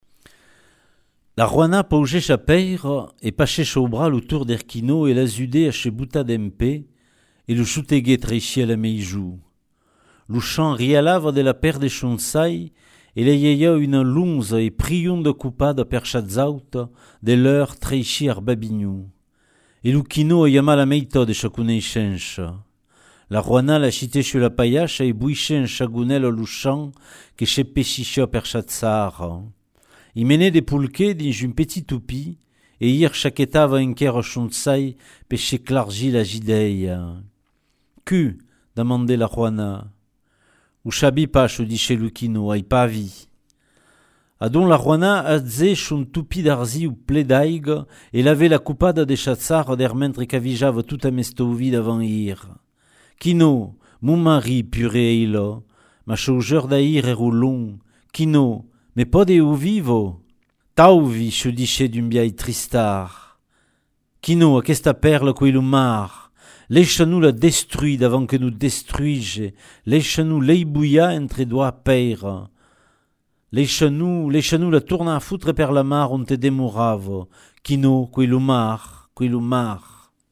– “dau” ven “del” e se prononça “der” ;
– las finalas en “-au”/”-eu” venen “-al”/”-el” e se prononcen “-ar”/”-er” ;
– las finalas en “-lh” se prononcen “-r” : “uelh” se dit “eur”, “ilh” se dit “ir” ;
– “es” (coma dins “escòla”) se dit “ès” en luòc de “èï” ;